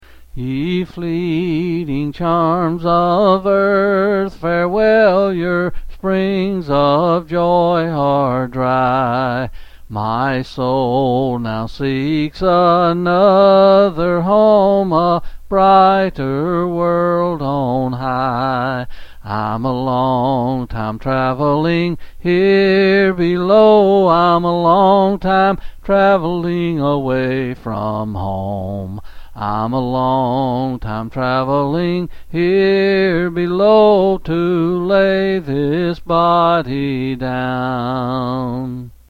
Quill Selected Hymn
C. M.